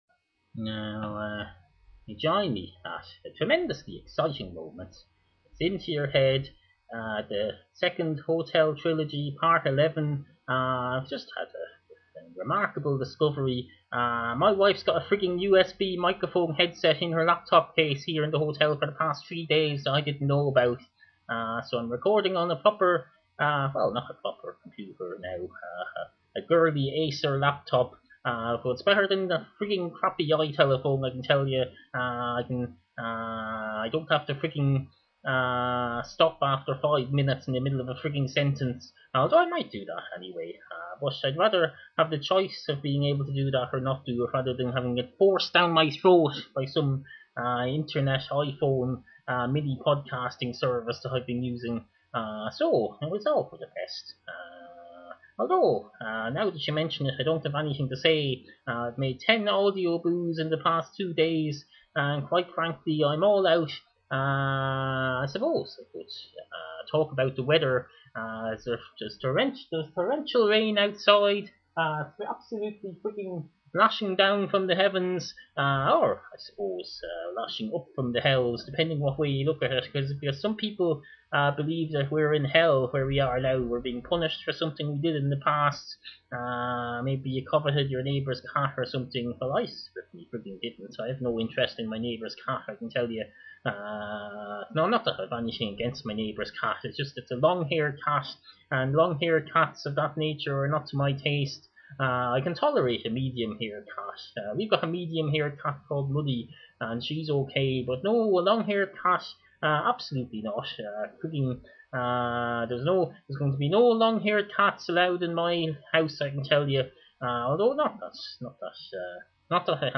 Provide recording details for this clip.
Excuse the variable sound quality, but here’s the last batch of recent recordings from the comfort of a hotel room somewhere in Ireland.